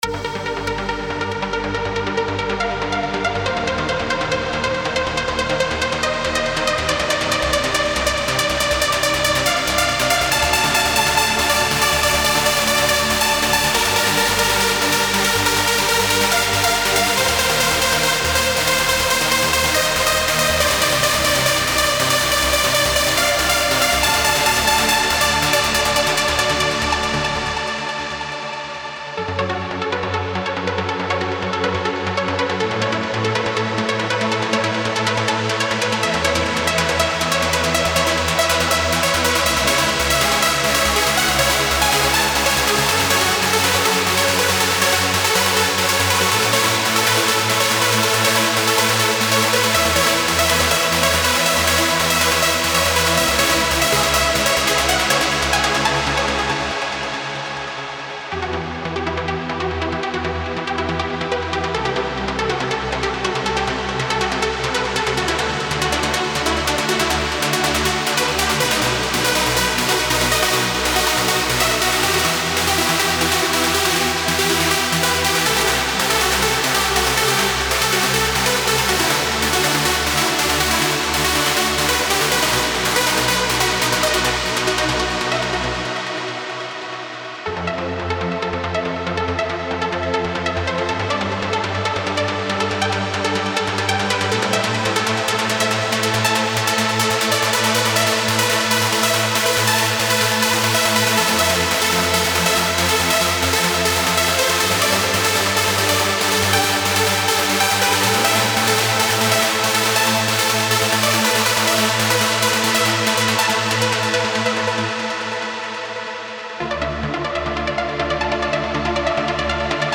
Ambient Chill Out / Lounge Trance Uplifting Trance
Lead, Bass, Pad for any Kit
FLP File included ( Spire & Serum for sounds )